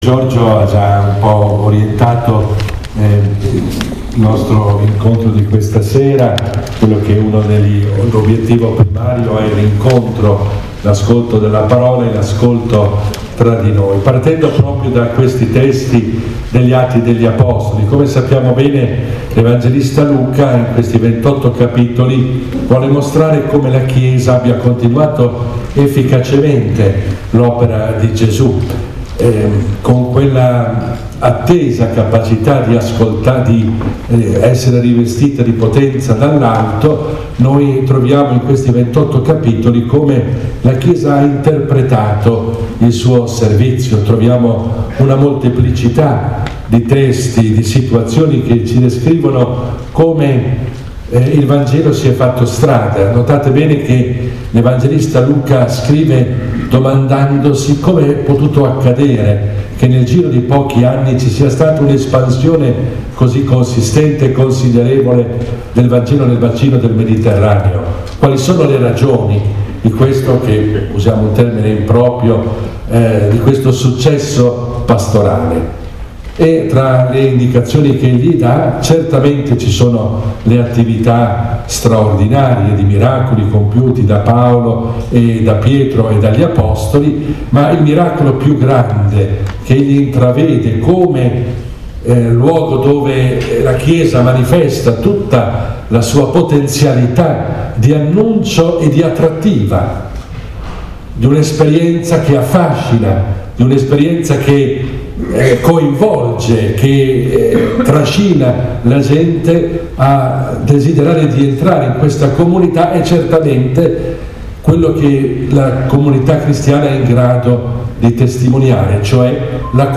Nella registrazione accessibile qui sopra, è possibile riascoltare le parole che il vescovo Giacomo, Lunedì 20 febbraio scorso, ha rivolto a quanti si sono riuniti a Novellara in un incontro con lui per un momento di preghiera, lettura di un brano biblico, introduzione del Vescovo e dibattito sui temi pastorali più attuali.